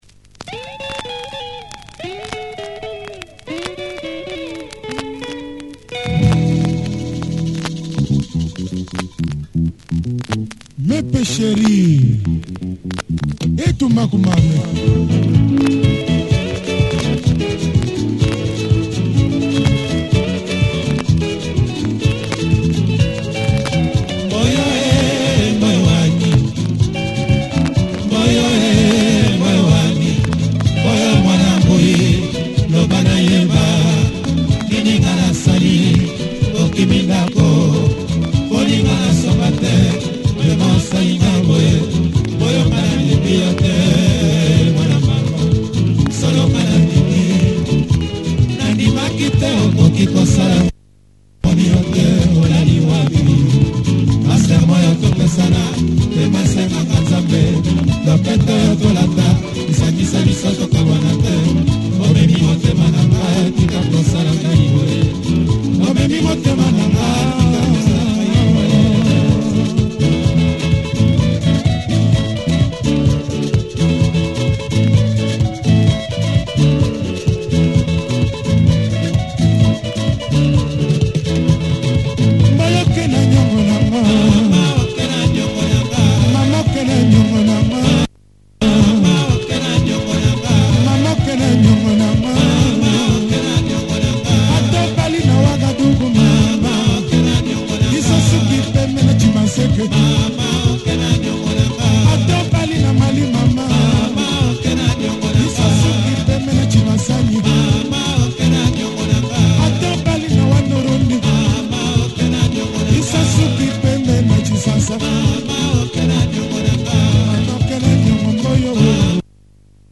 Nice late Lingala sounds